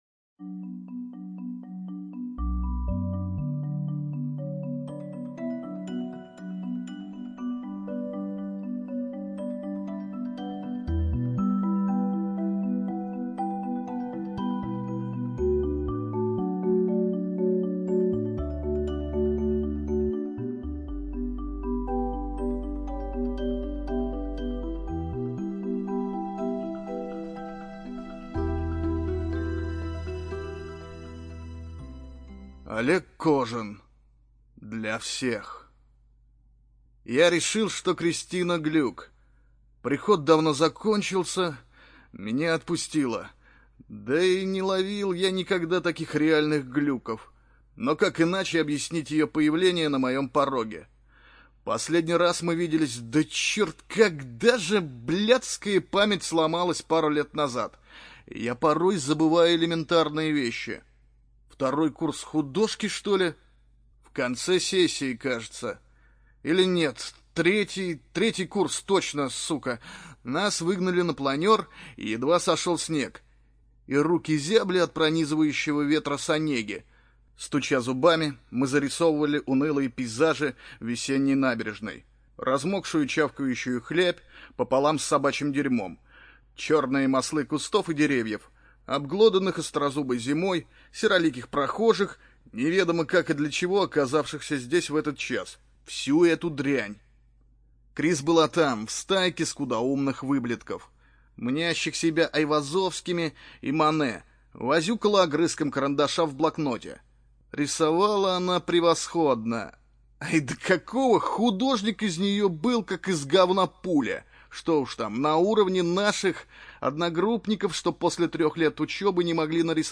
ЖанрУжасы и мистика